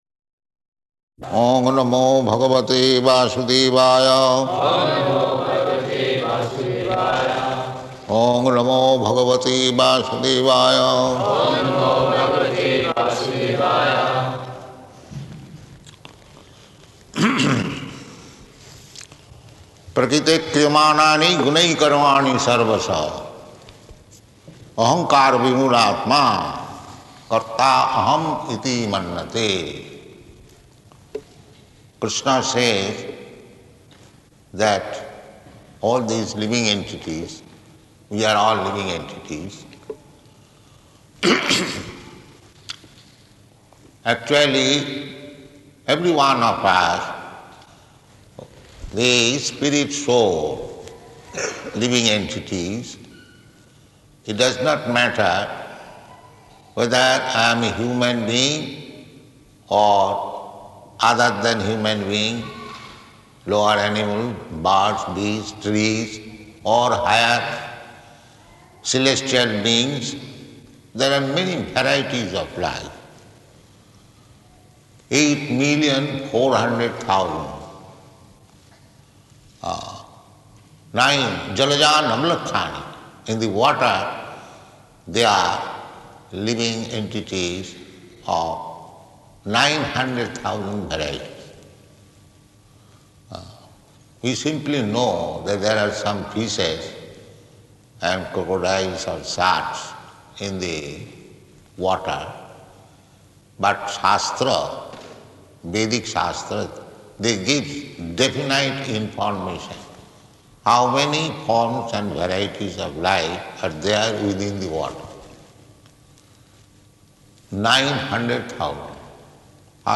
Bhagavad-gītā 3.27 at Town Hall
Bhagavad-gītā 3.27 at Town Hall --:-- --:-- Type: Bhagavad-gita Dated: June 27th 1974 Location: Melbourne Audio file: 740627BG.MEL.mp3 Prabhupāda: Oṁ namo bhagavate vāsudevāya.